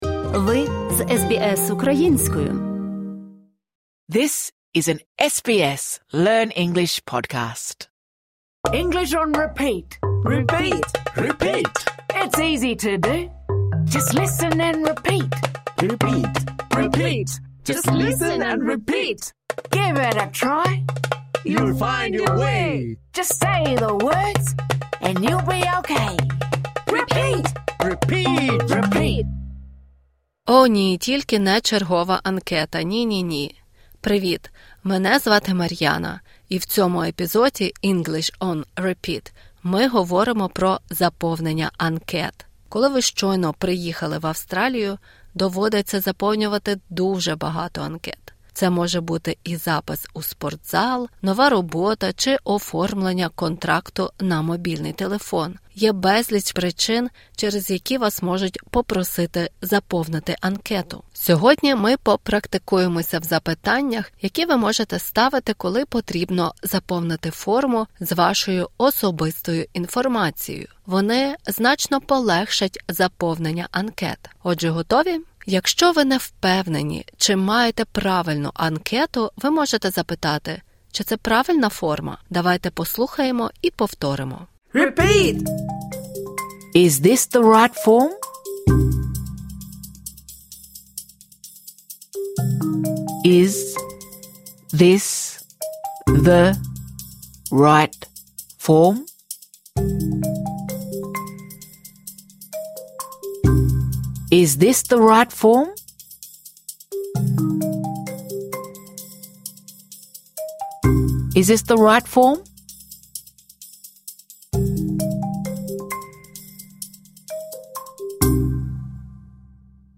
Цей урок призначений для початківців.